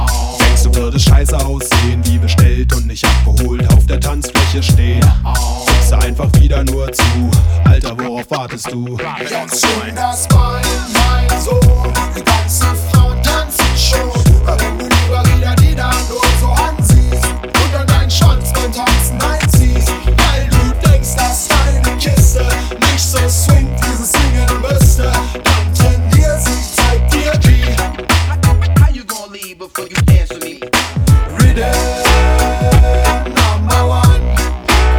Жанр: Реггетон